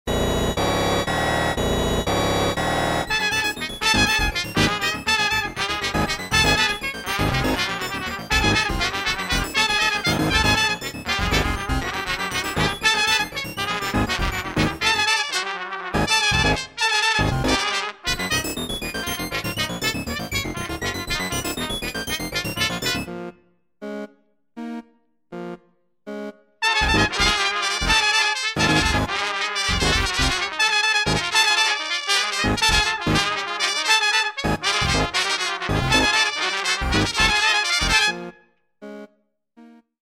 for flute, violin, cello and percussion